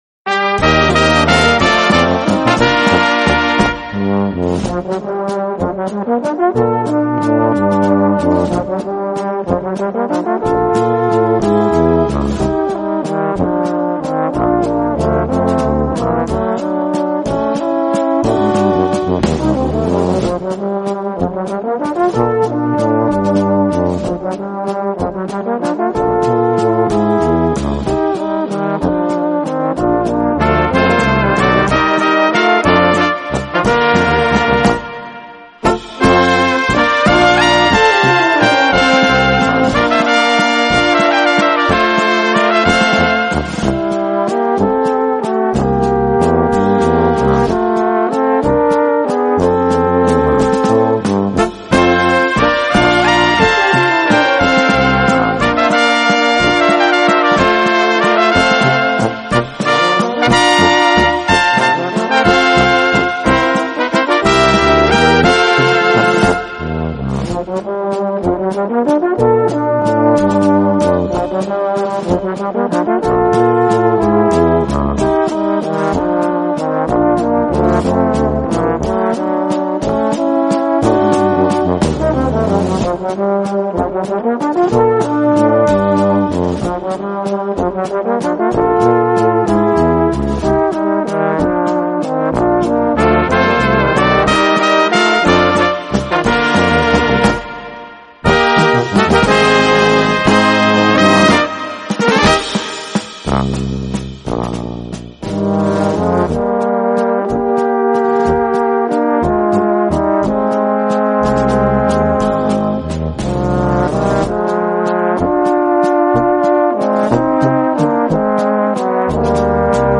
Gattung: Walzer für kleine Besetzung
Besetzung: Kleine Blasmusik-Besetzung
Wunderschöner Walzer im Innsbrucker Arrangement.